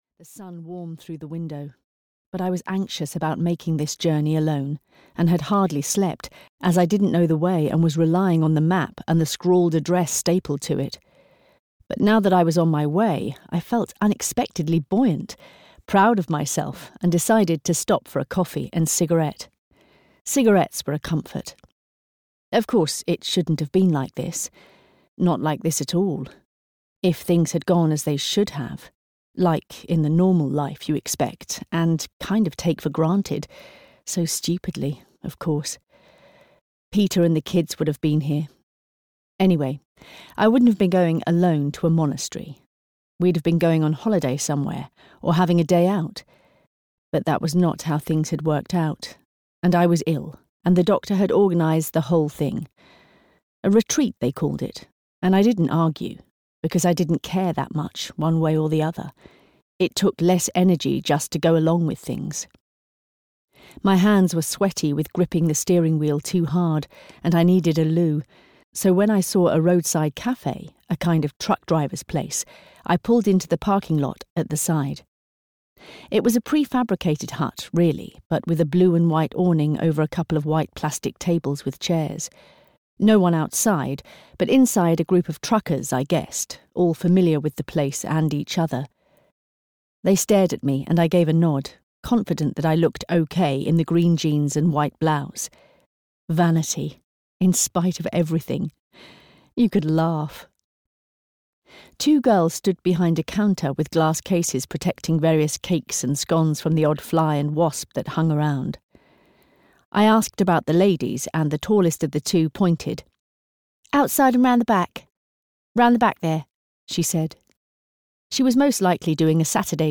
Ask Me to Dance (EN) audiokniha
Ukázka z knihy